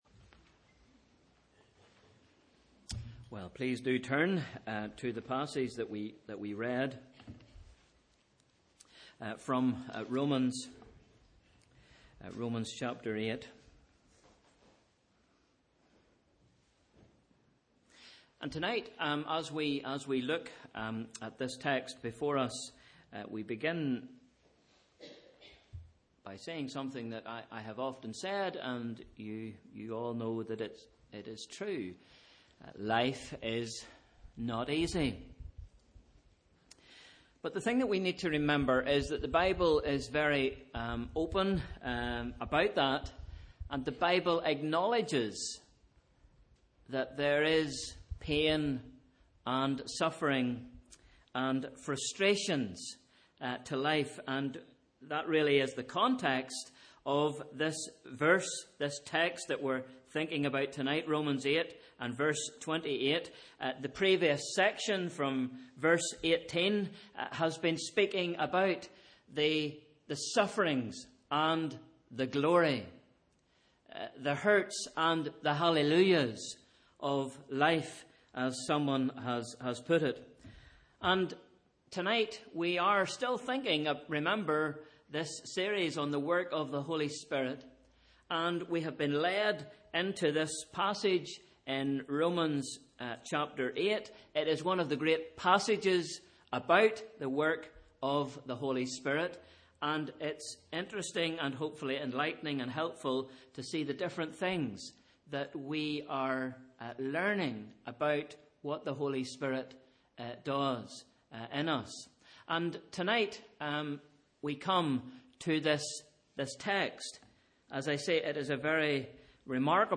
Sunday 6th March – Evening Service